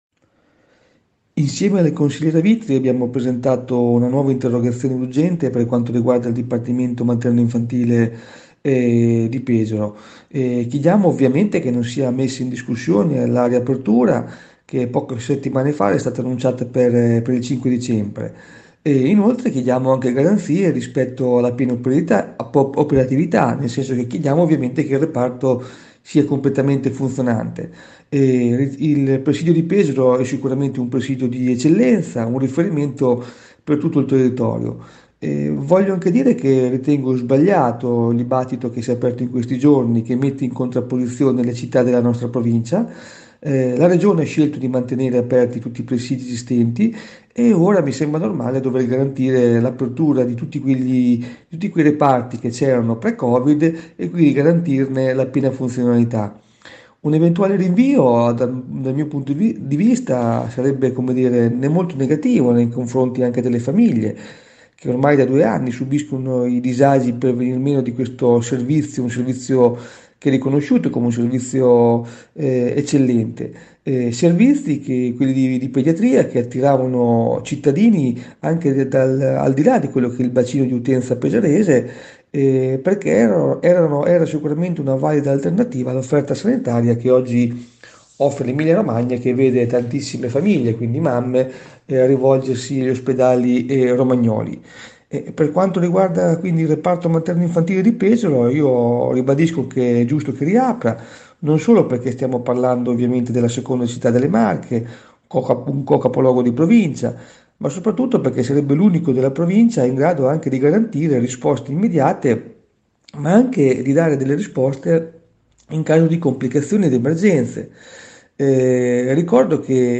Intervento del Consigliere Regionale, Andrea Biancani ai nostri microfoni.”Non sia messa in discussione la riapertura annunciata per il 5 dicembre, chieste garanzie sulla piena operatività.